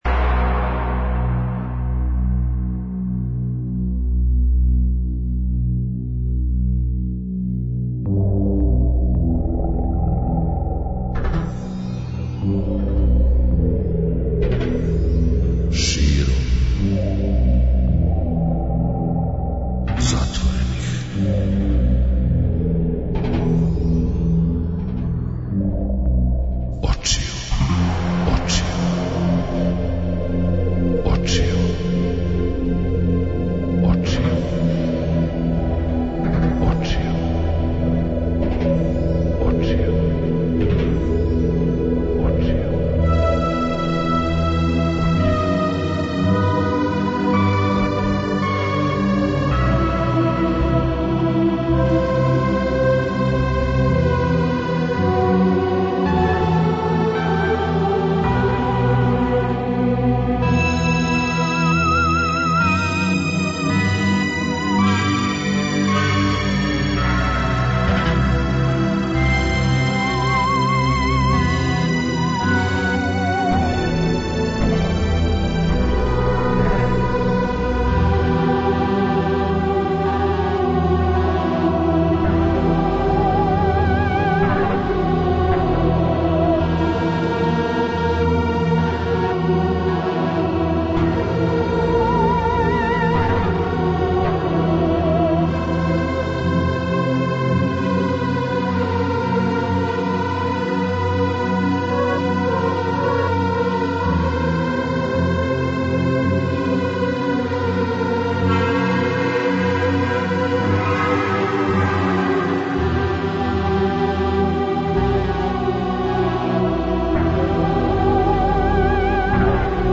А за најхрабрије међу вама резервисали смо последњи сат емисије када ћемо се бавити урбаним легендама. Будите са нама и сазнајте које су то најпознатије приче пуне мистике, без познатог аутора, које се иначе причају уз логорску ватру а само вечерас директно из студија Двестадвојке.